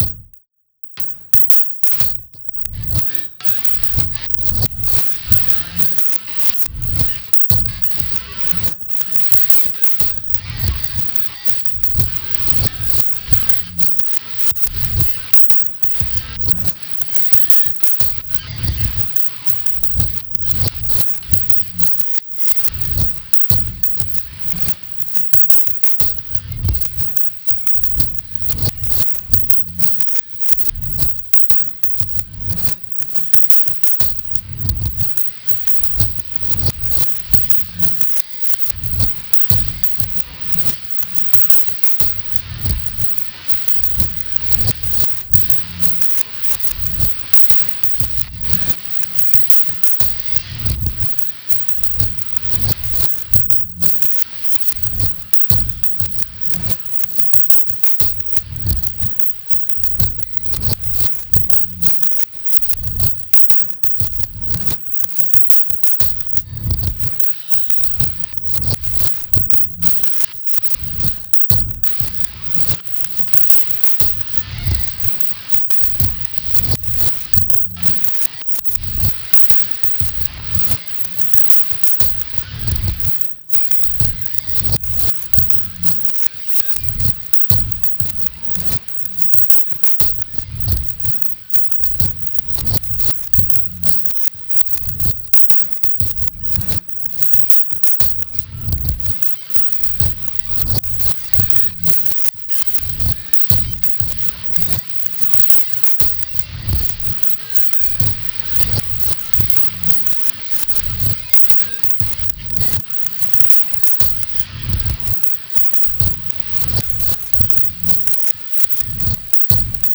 各曲ともに聴き進む内に、独特異様な酩酊感に包まれていくような...。
どうかこの不可思議な律動に身を委ね、あなたの魂を奔放に舞踏させながら、お楽しみください。